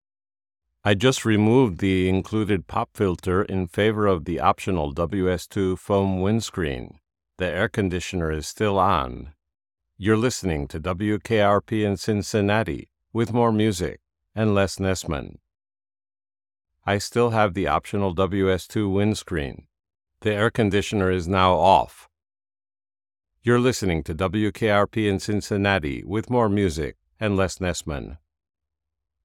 Test recording 2, after one-click processing with Descript «Studio Sound»
This recording is the same one you heard above, after the one-click «StudioSound» from Descript.
NT1-Signature-Edition-WS2-Studio-Sound.wav